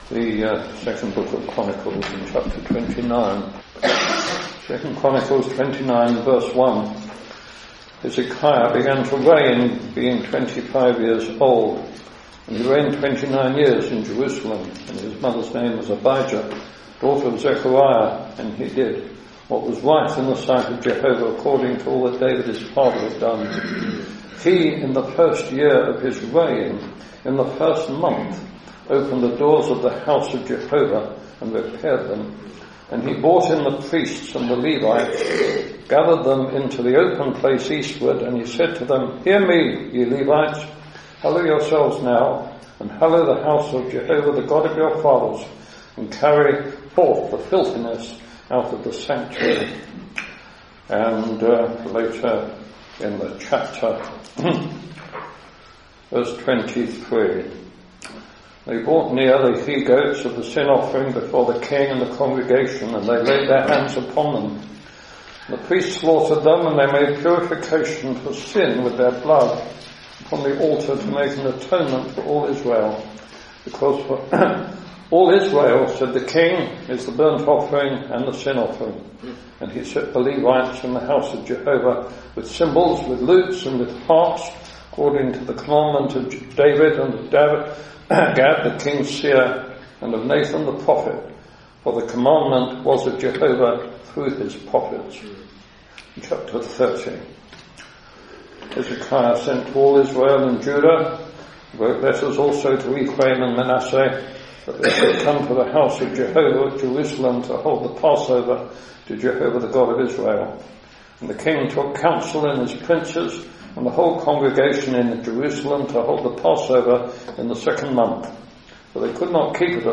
Hezekiah was a king of Judah and the scriptures says he did what was right in the sight of the Lord. We can learn a lot from Hezekiah and in this Address you will hear about his reign and the things he did to cleanses the House of God.